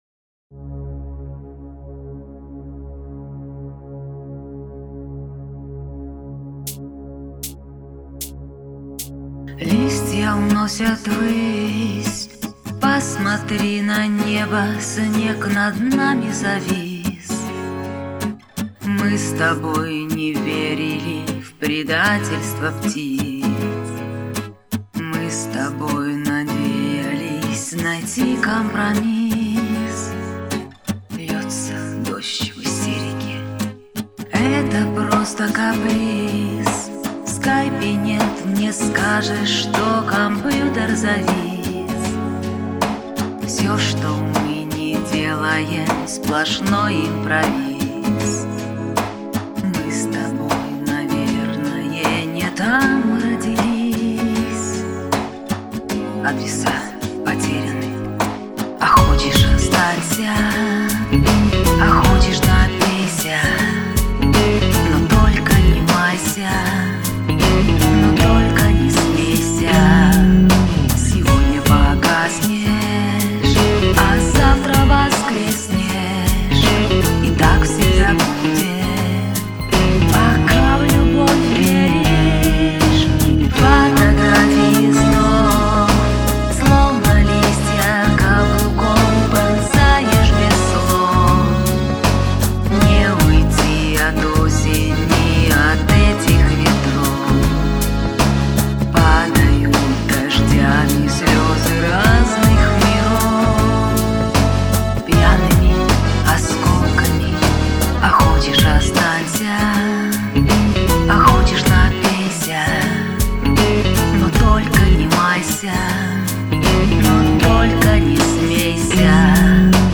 А затем "тишь и благодать", так всё ровненько,
и задушешевненько... полное отсутствие необходимой резкости.